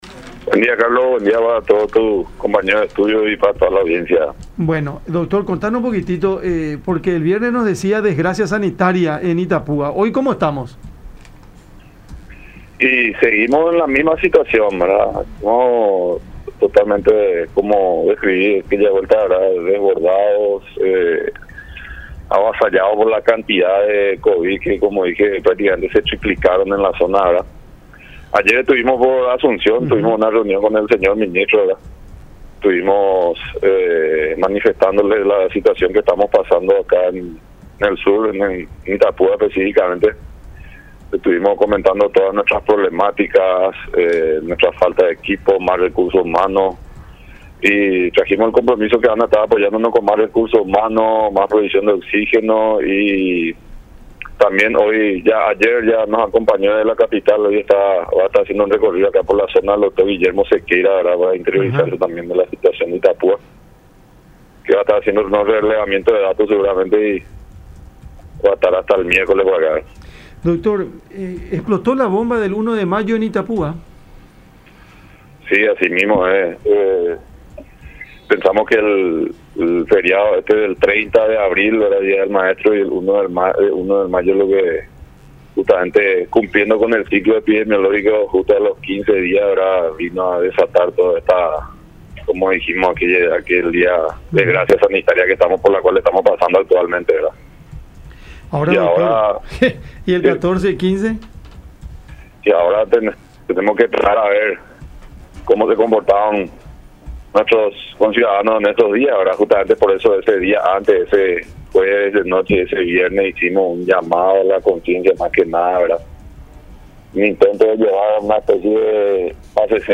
en conversación con Cada Mañana a través de La Unión.